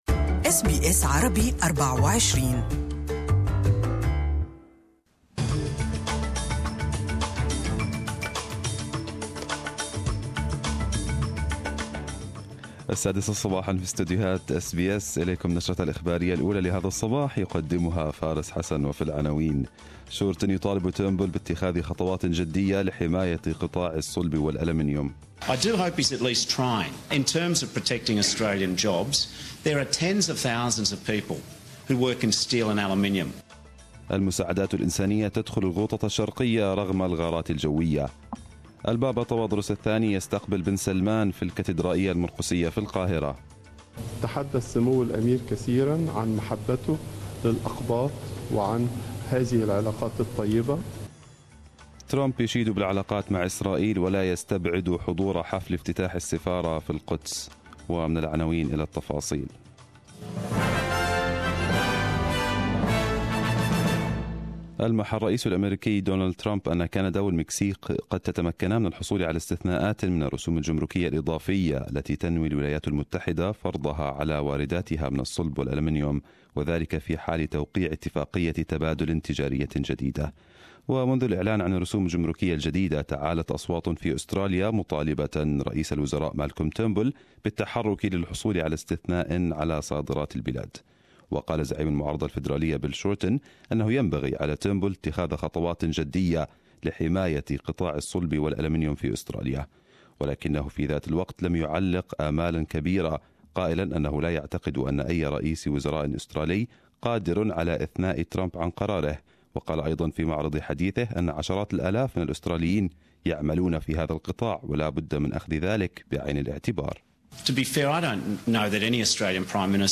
Arabic News Bulletin 06/03/2018